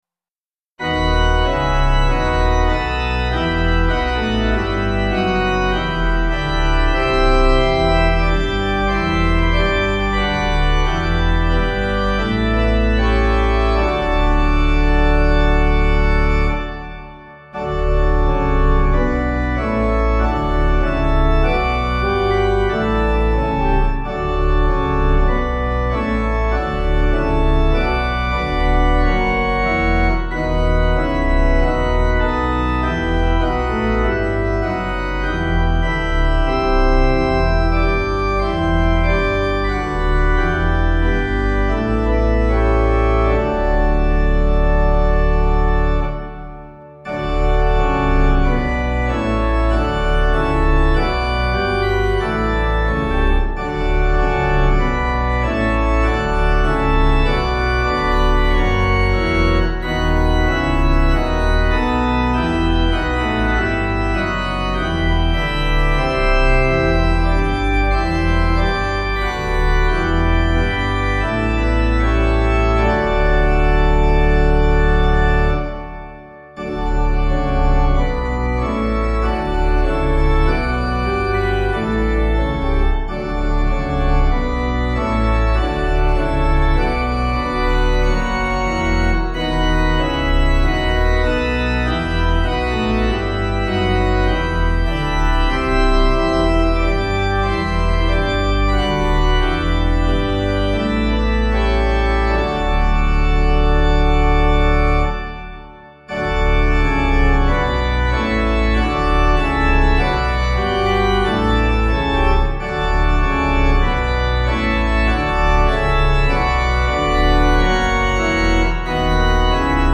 Key: C Major